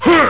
Throw-male.wav